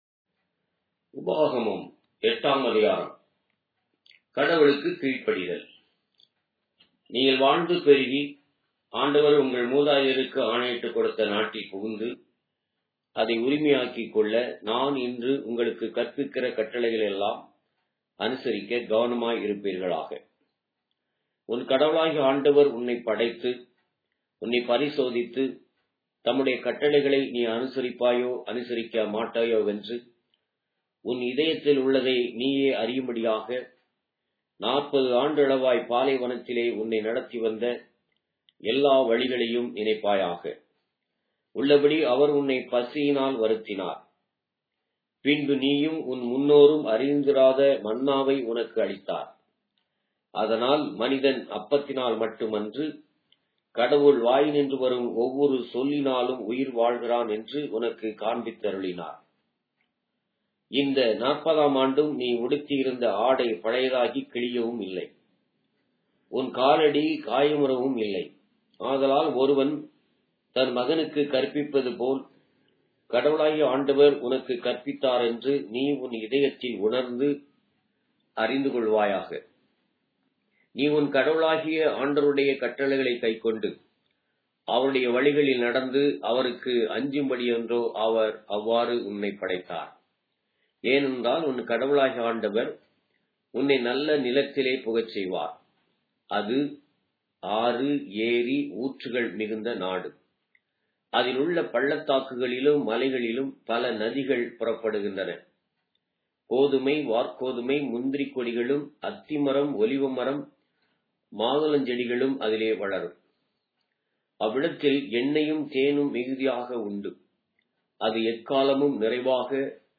Tamil Audio Bible - Deuteronomy 4 in Rcta bible version